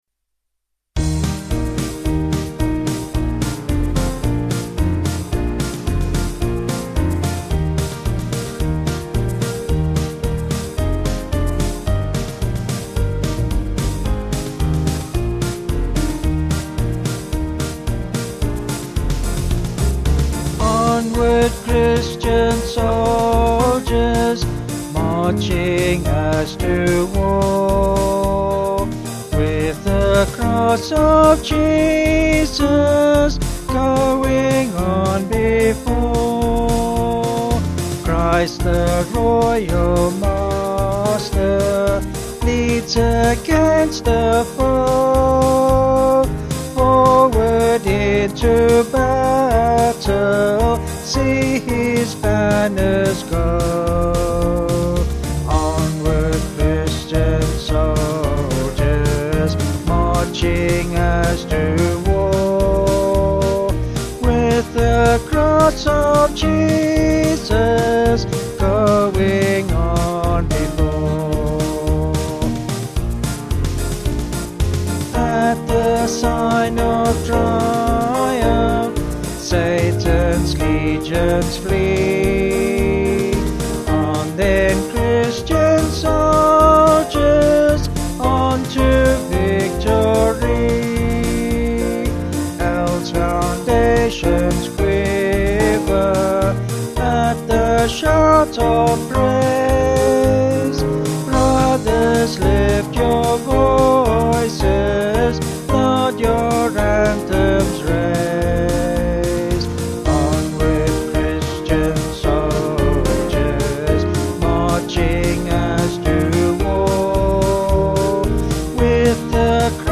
Vocals and Band   329.1kb Sung Lyrics